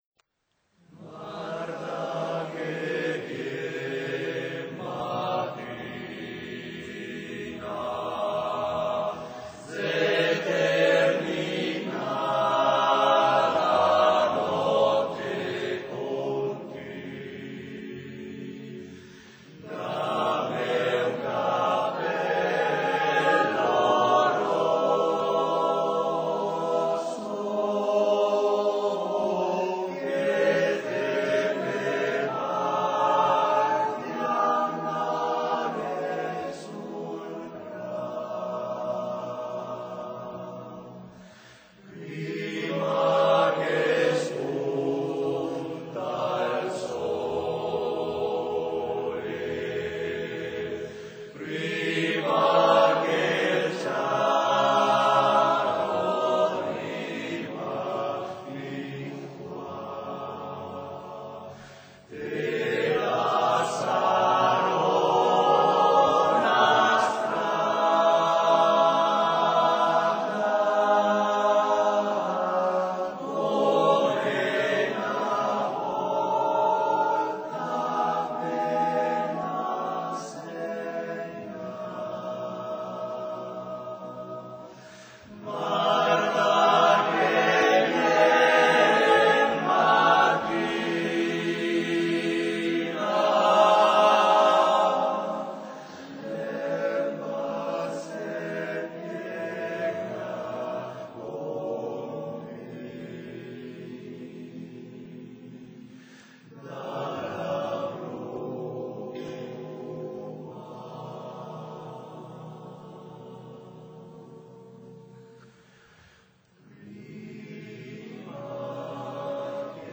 REPERTORIO DEL CORO ANA AVIANO